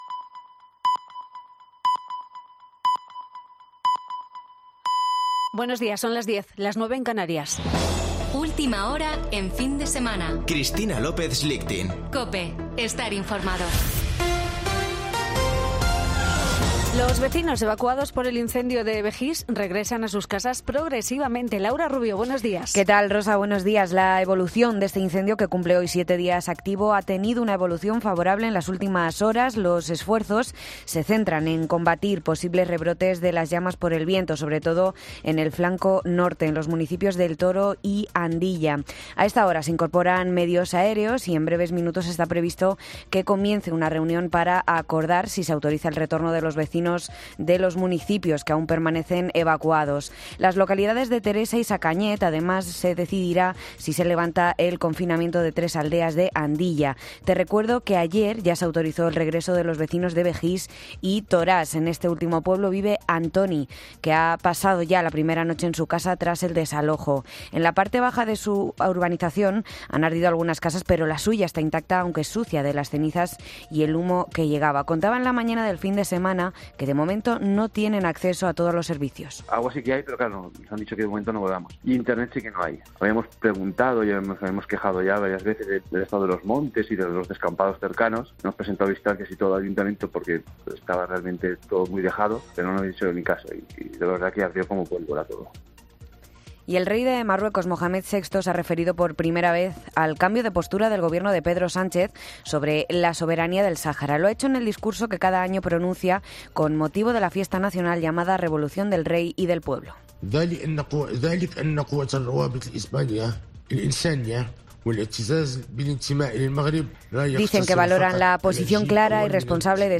Boletín de noticias de COPE del 21 de agosto de 2022 a las 10.00 horas